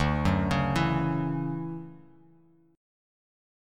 DM9 Chord
Listen to DM9 strummed